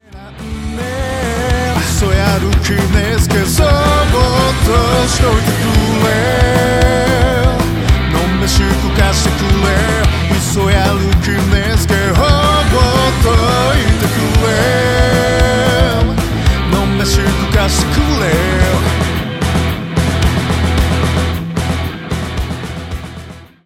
ロックバンド
ちょっぴりいねよな土着ロック 聴いてみませんか？